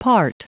Prononciation du mot part en anglais (fichier audio)
Prononciation du mot : part